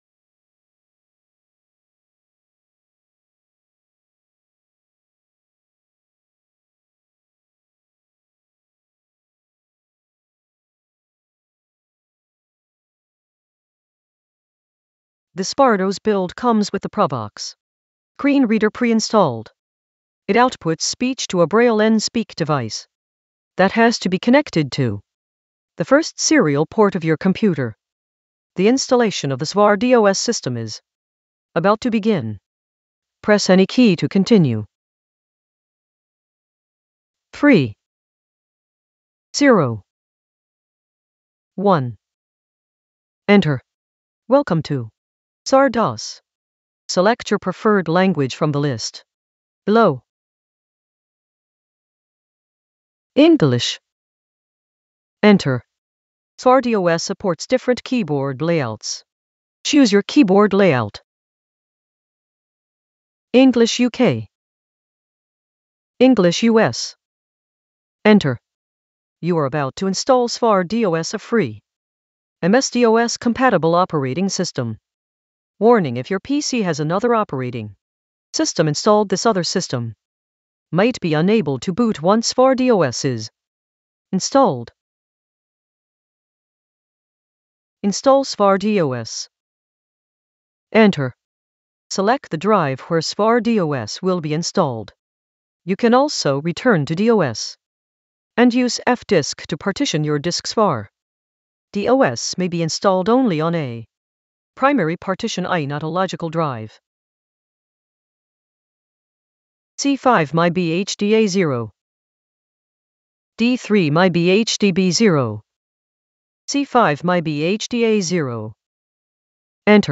It starts after some 15s of silence. http
The TTS part is not perfect, but probably as good as it can be for a quick hack I have put together in a a couple of hours. Provox is sometimes cutting the phrases at odd places. I guess this did not matter back in the day with actual BNS devices as these were probably talking in some monotonous semi-robotic tone, but now it makes piper do strange inflections sometimes.
svardos-emubns-piper.mp3